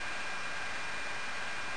FGOS_Avionics.wav